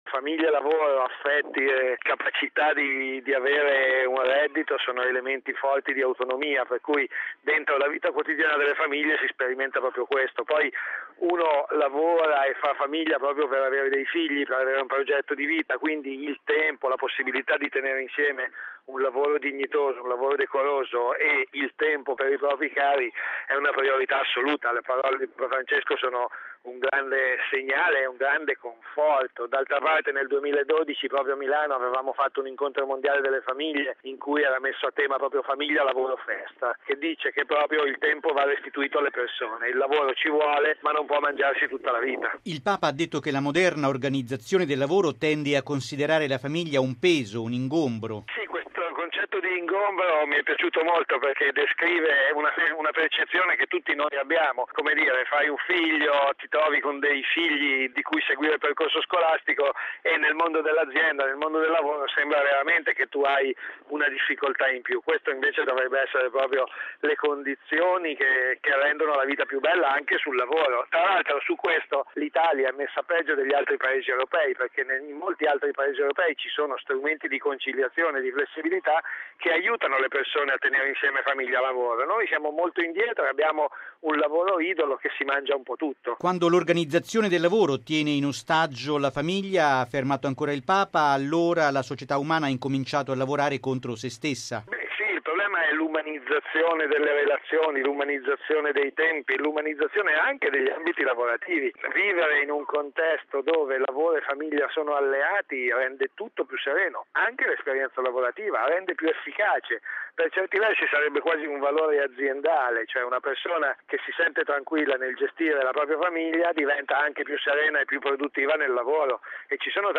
Logo 50 Radiogiornale Radio Vaticana